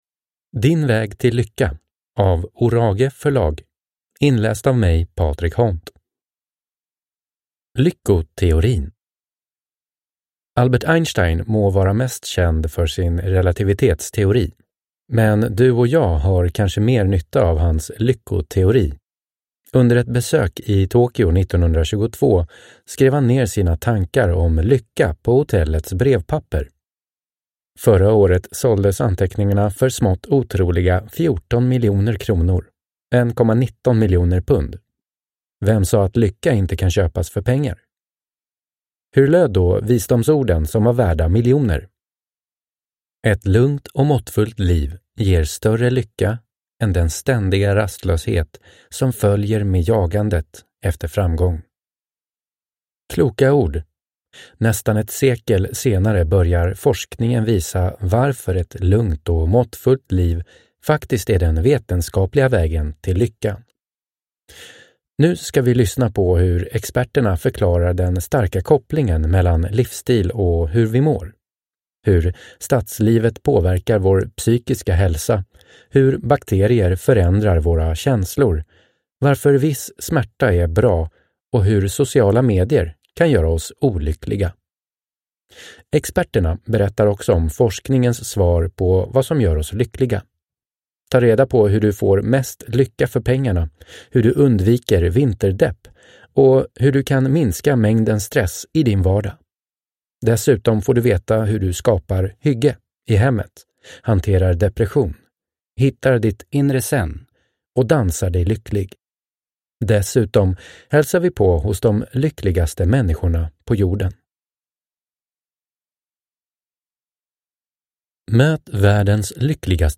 Din väg till lyckan – Ljudbok – Laddas ner